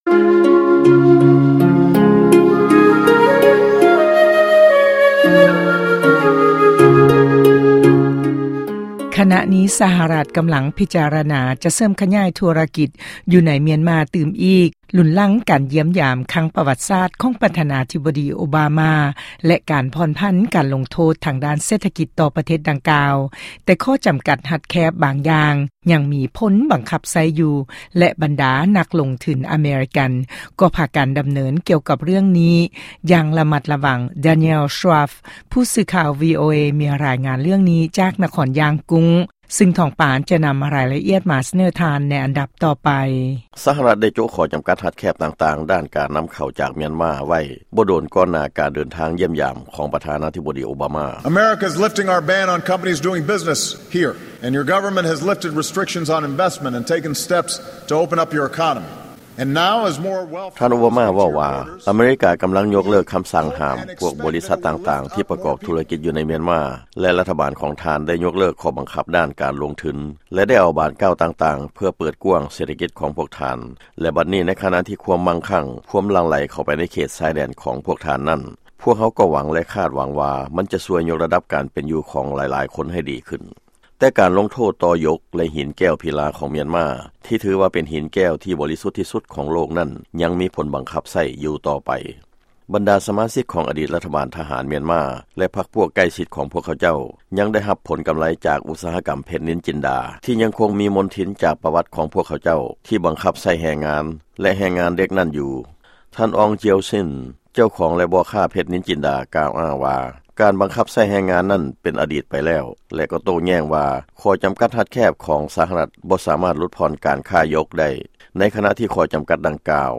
ຟັງລາຍງານ ກ່ຽວກັບສະຫະລັດຈະຂະຫຍາຍເສດຖະກິດໃນມຽນມາ